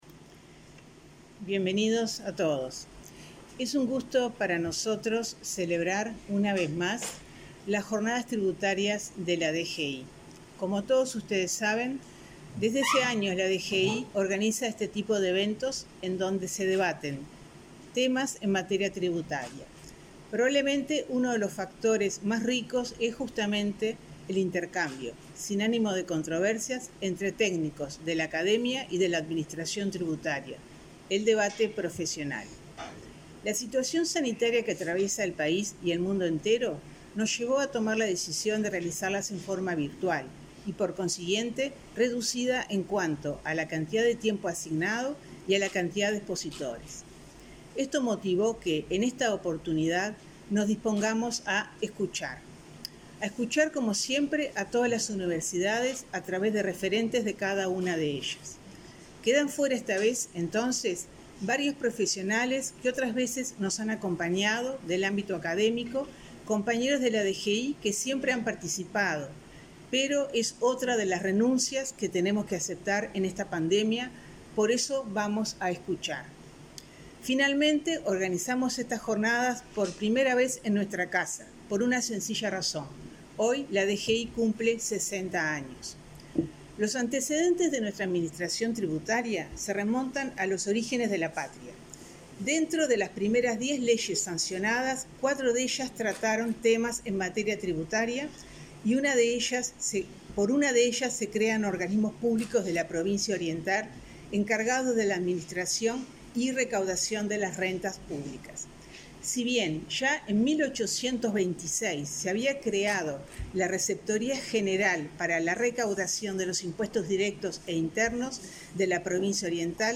Jornadas Tributarias 2020 analizarán temáticas vinculadas con la recuperación económica luego de la pandemia 01/12/2020 Compartir Facebook X Copiar enlace WhatsApp LinkedIn En el marco de los 60 años de la Dirección General Impositiva, este lunes 30 el subsecretario de Economía y Finanzas, Alejandro Irastorza, y la directora general de Rentas, Margarita Faral, inauguraron las Jornadas Tributarias 2020, que desarrollarán aportes académicos sobre medidas tributarias para la recuperación económica, la tributación ante la digitalización de la economía y los retos de la fiscalidad, entre otros temas.